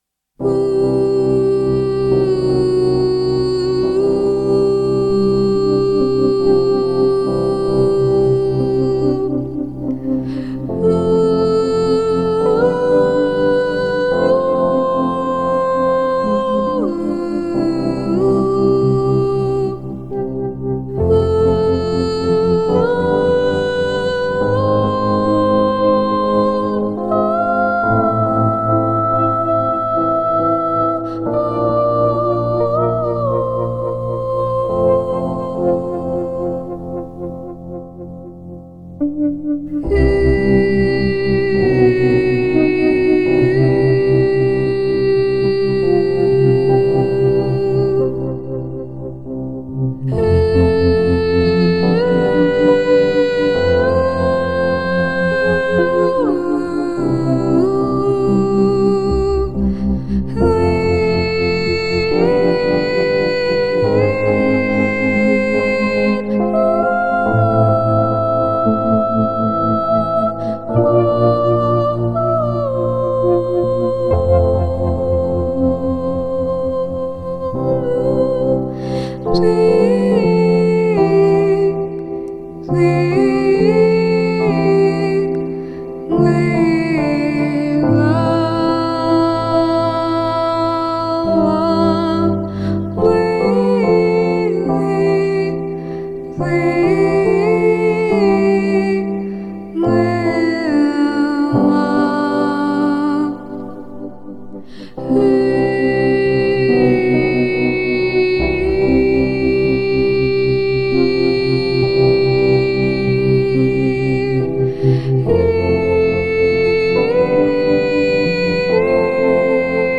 This combination results in a very emotionally charged song